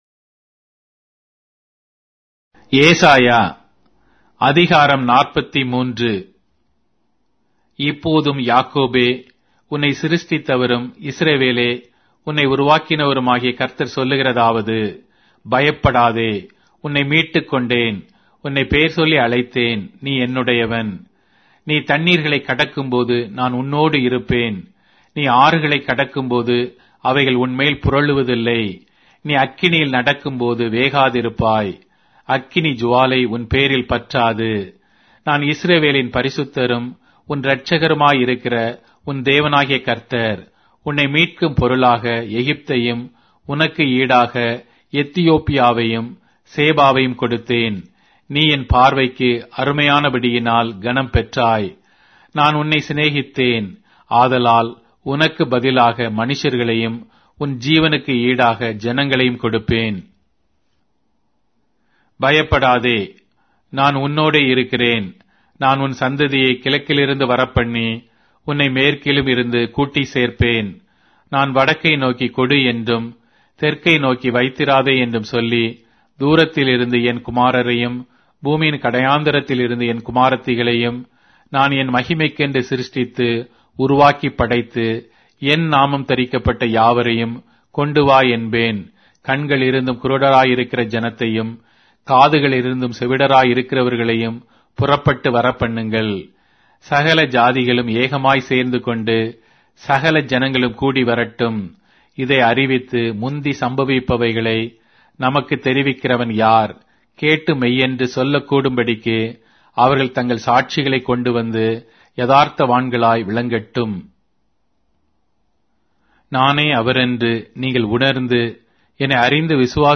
Tamil Audio Bible - Isaiah 14 in Irvpa bible version